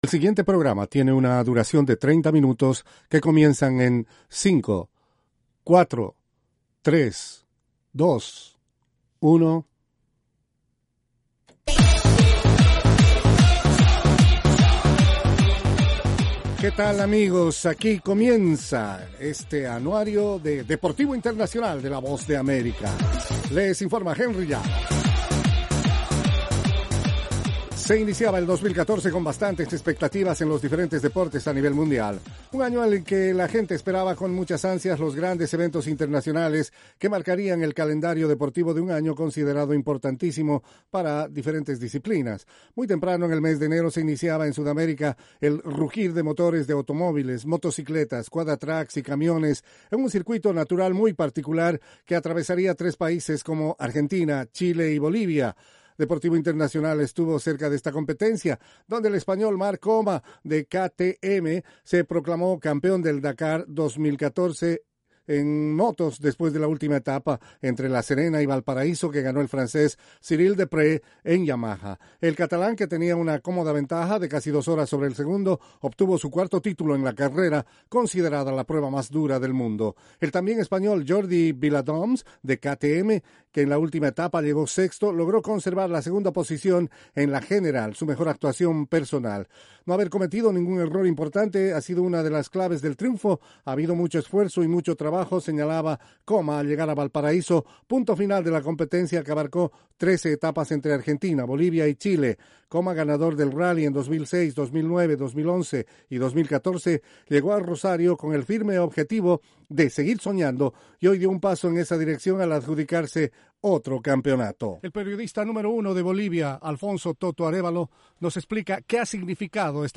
presenta las noticias más relevantes del mundo deportivo desde los estudios de la Voz de América.